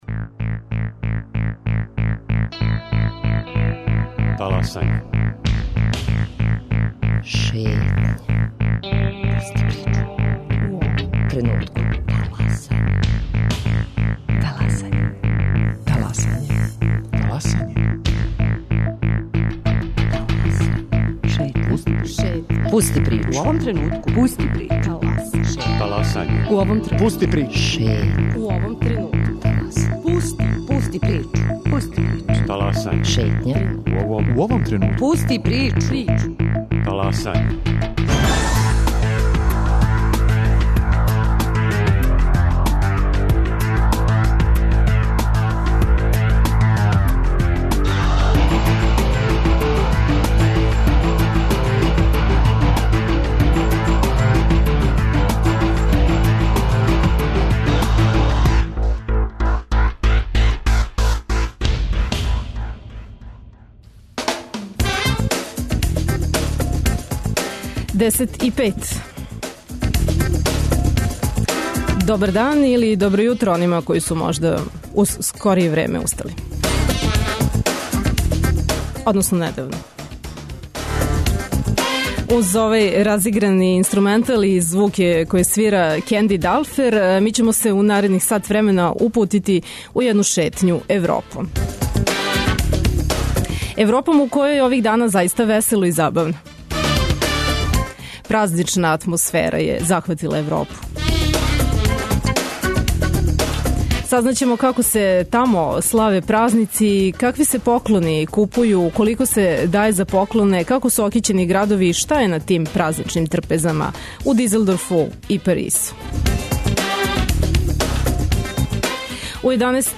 Предновогодишњу и Божићну атмосферу из европских градова, пренеће нам наши репортери. Уз њихову помоћ, шетаћемо Келном, Паризом, Москвом и уживати у претпразничним догађајима, вашарима, свиркама.